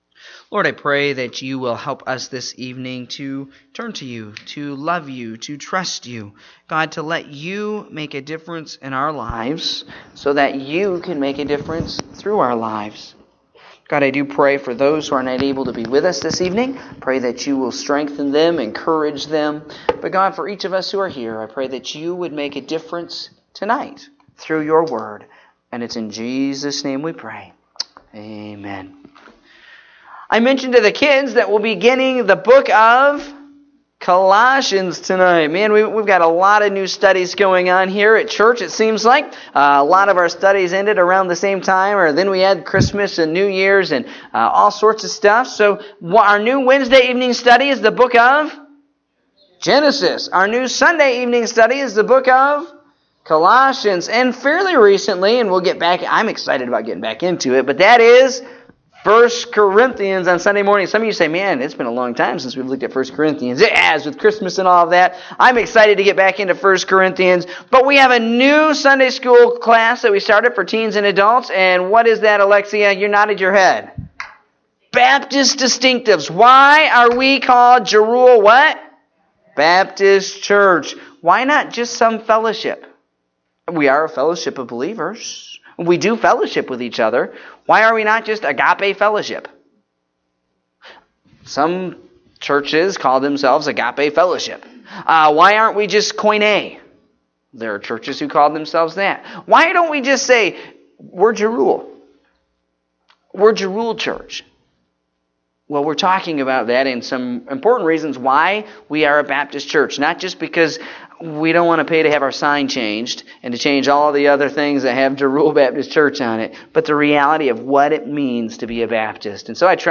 Evening Service (1/21/2018)